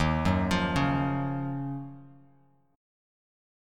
D#m9 chord